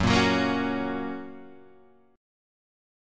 F# chord {2 4 4 3 2 2} chord